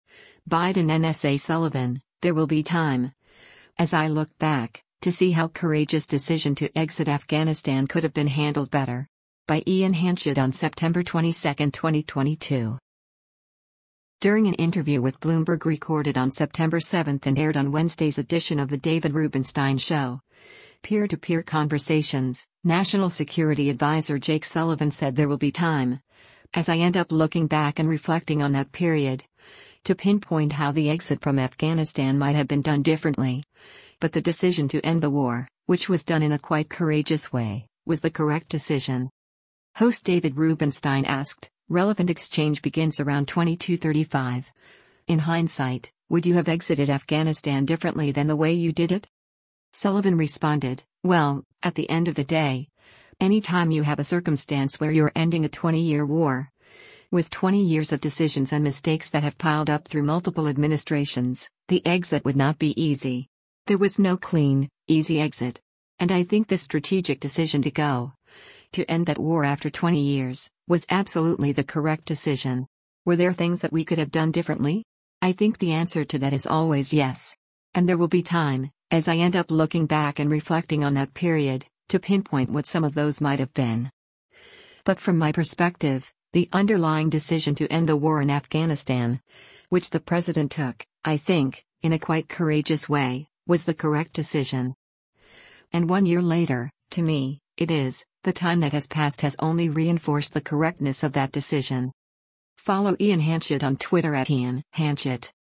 During an interview with Bloomberg recorded on September 7 and aired on Wednesday’s edition of “The David Rubenstein Show, Peer to Peer Conversations,” National Security Adviser Jake Sullivan said “there will be time, as I end up looking back and reflecting on that period, to pinpoint” how the exit from Afghanistan might have been done differently, but the decision to end the war, which was done “in a quite courageous way, was the correct decision.”